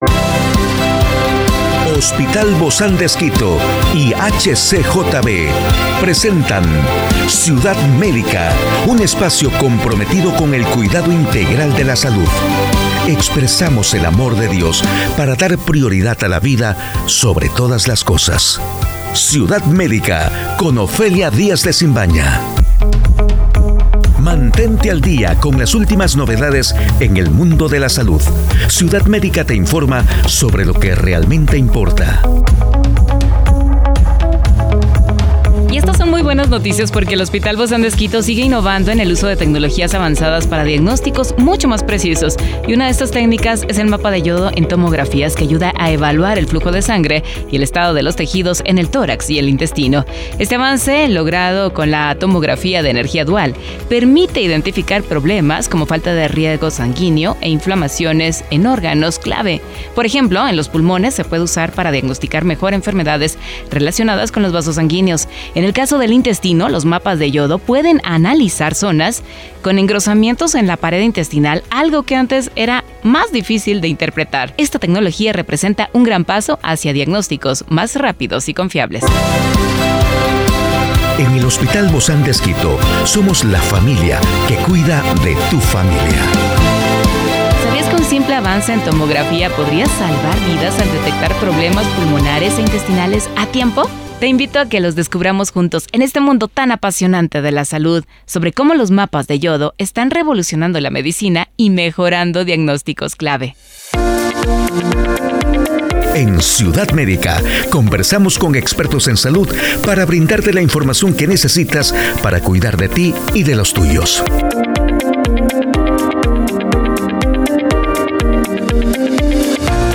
¡No te pierdas esta fascinante conversación con nuestros expertos! Descubre cómo el yodo juega un papel clave en mejorar la precisión de los diagnósticos por tomografía y su impacto en la medicina.